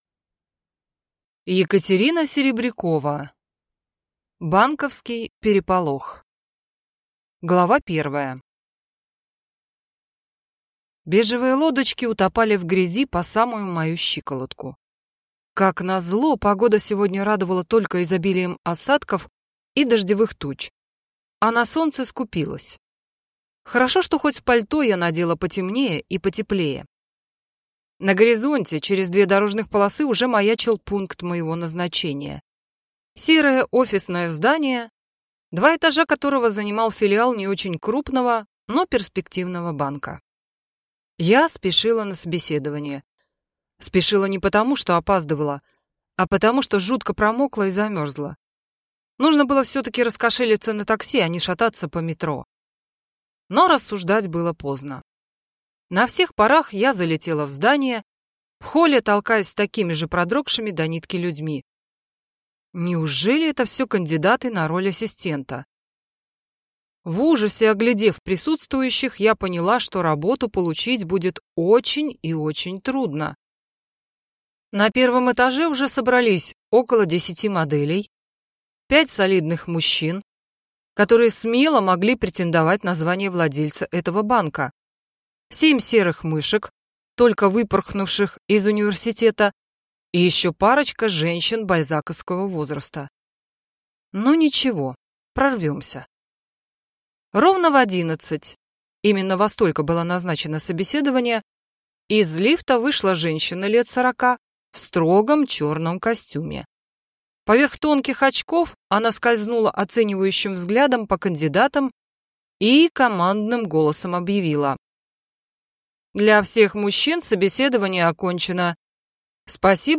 Аудиокнига Банковский переполох | Библиотека аудиокниг